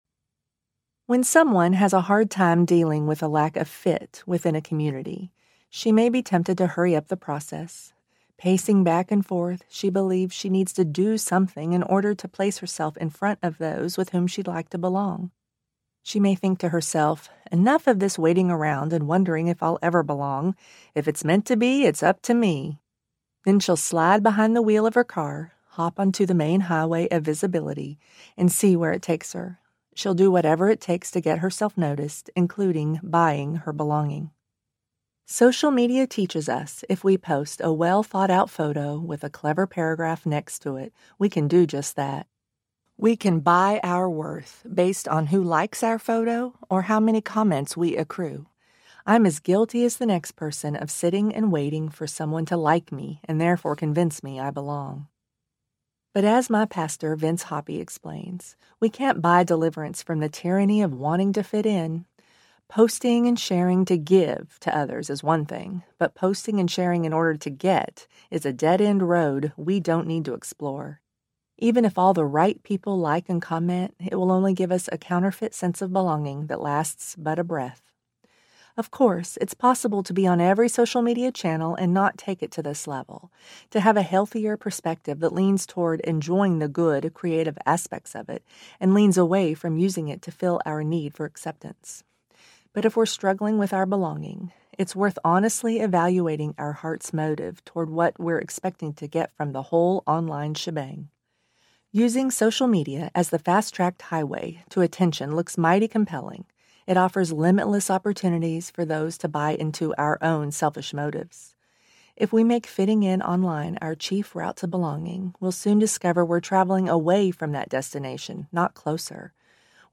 Back Roads to Belonging Audiobook
5.7 Hrs. – Unabridged